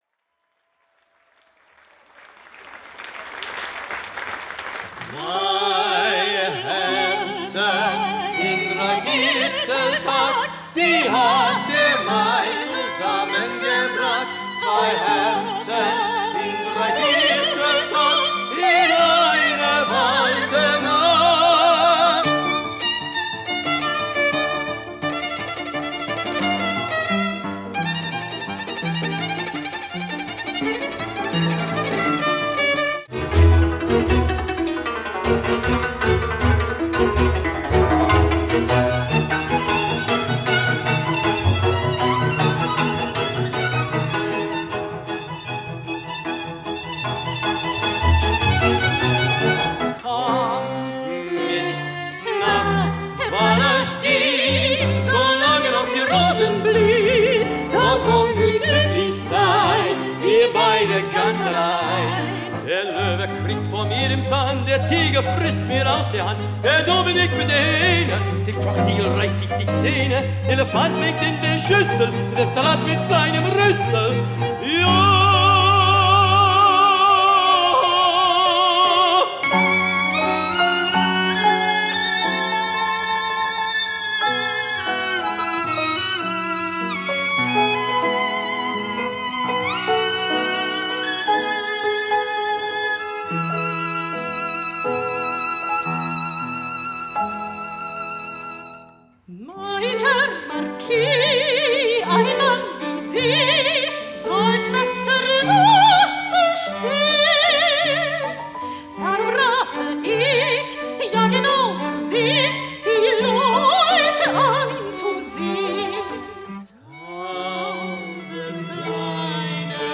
WIENER OPERETTEN SHOW
unvergessliche Melodien
Das große Ensemble umfasst Sopran, Tenor, Violine, Chello sowie Piano.
ViennaOperettaEnsemble_medley_short.mp3